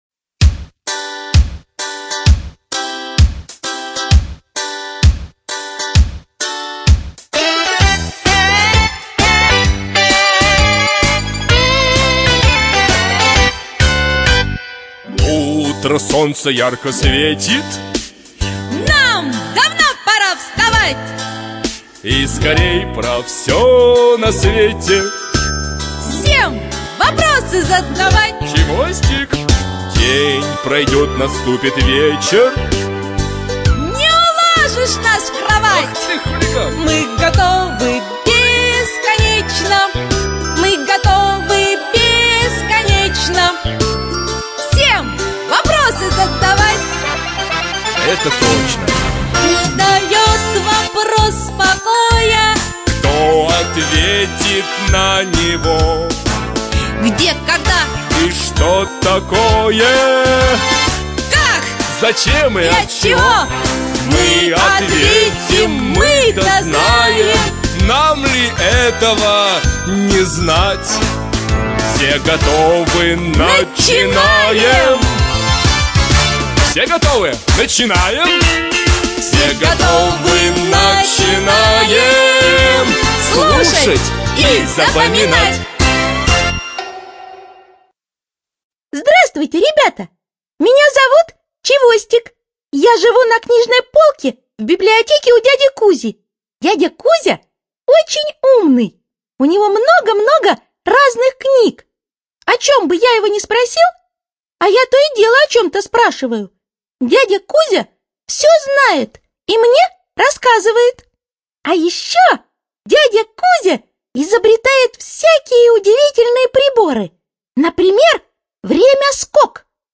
АвторАудиоэнциклопедия для детей
ЖанрДетская литература, Наука и образование